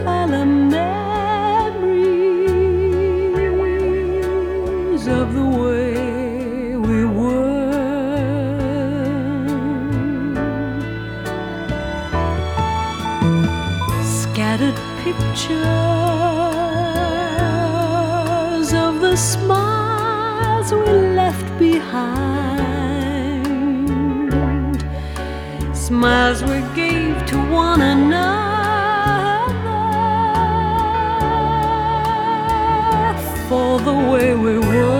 Жанр: Поп / Рок / Музыка из фильмов / Саундтреки